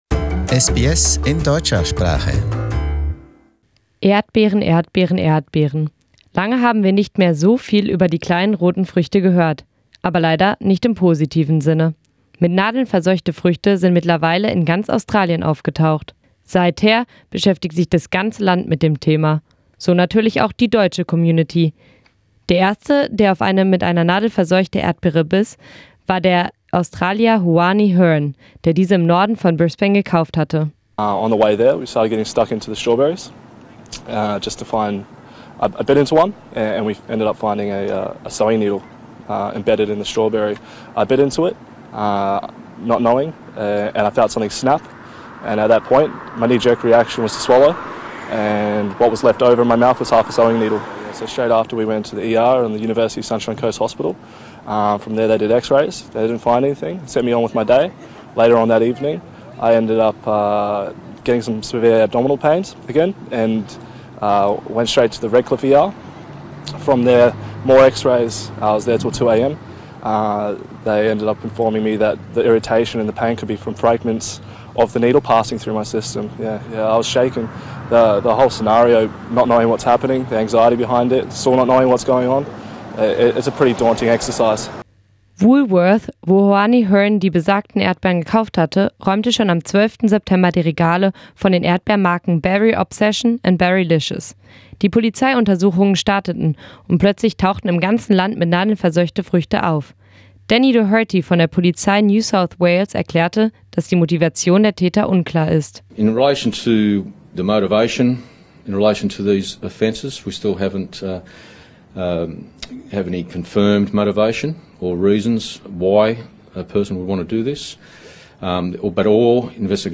Strawberries and other fruit contaminated with needles: The government has just announced a reward of $100.000 for information leading to arrest the responsible people and a jail sentence of 15 years. This podcast wraps up the crisis and includes a German voice in Australia.